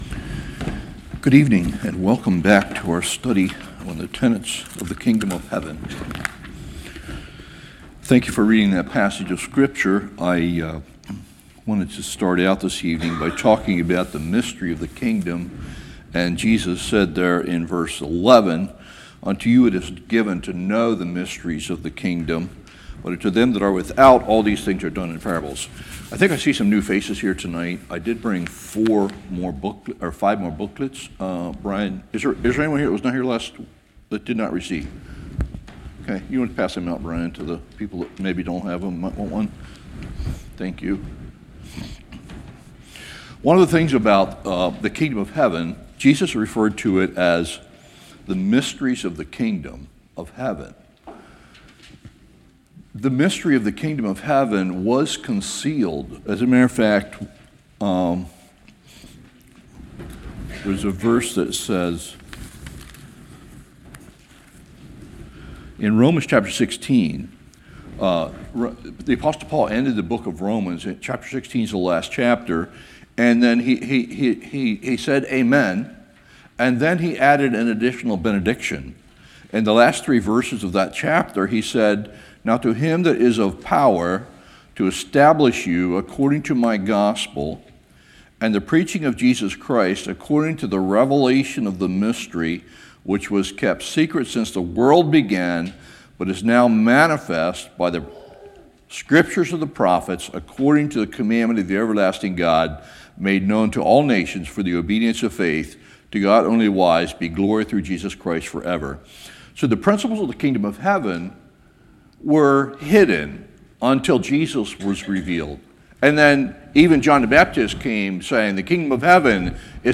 Service Type: Winter Bible Study